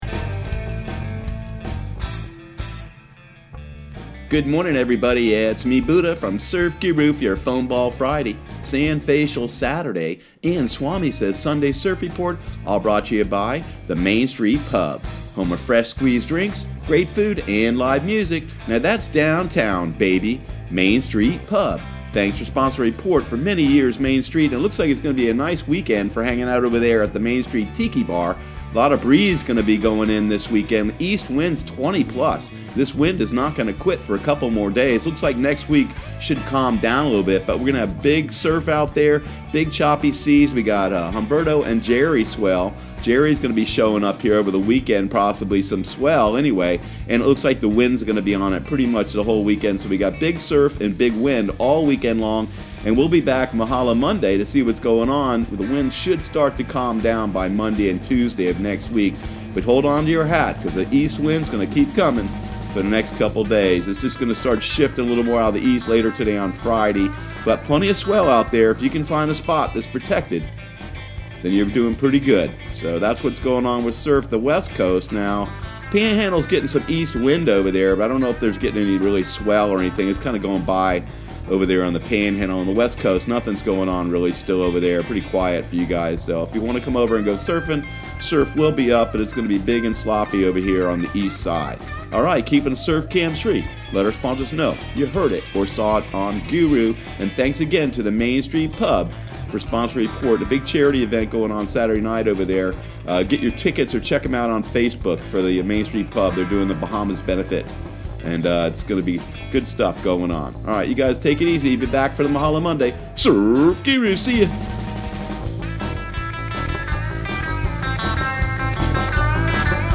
Surf Guru Surf Report and Forecast 09/20/2019 Audio surf report and surf forecast on September 20 for Central Florida and the Southeast.